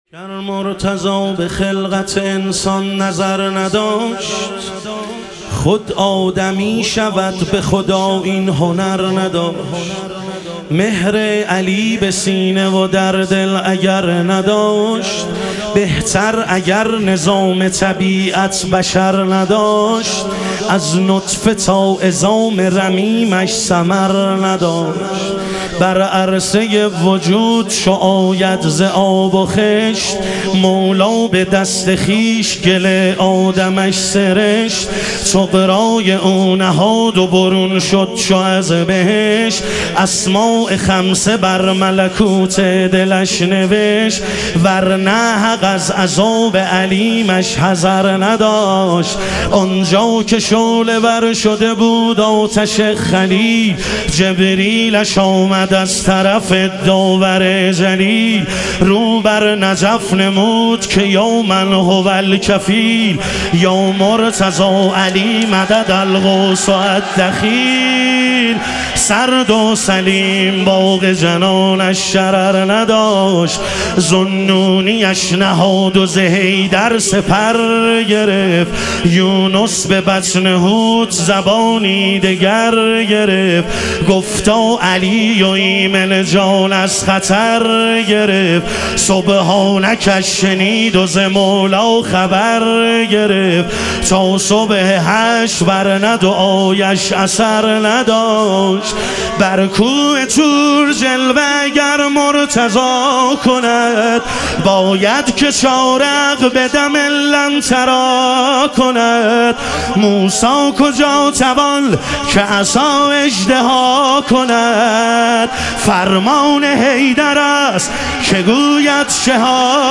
مدح و رجز
شب ظهور وجود مقدس حضرت امیرالمومنین علیه السلام